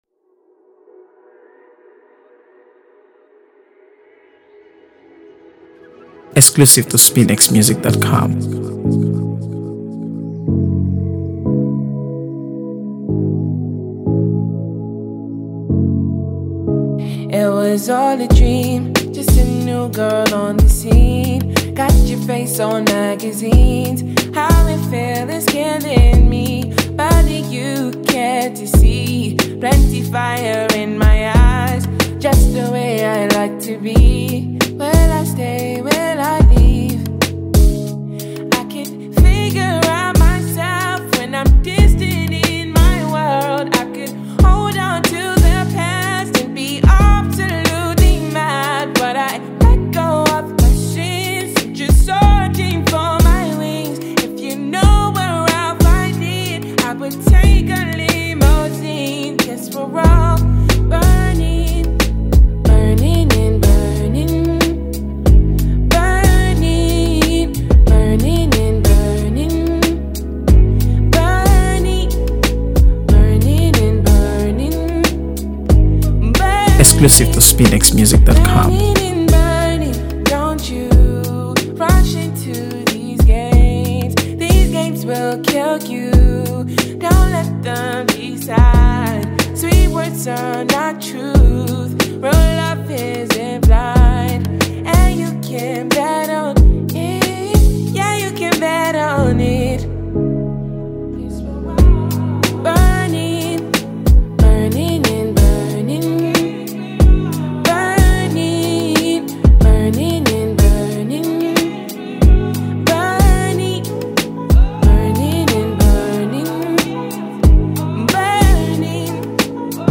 AfroBeats | AfroBeats songs
Grammy-winning Nigerian singer-songwriter